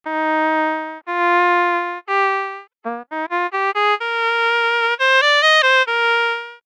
3. Single wavetable oscillator + real amplitude and frequency curves
(Same setup as with the previous example, except the frequency fluctuation of the synthesized sound is also realized using the measured pitch curve of the "real" performance.)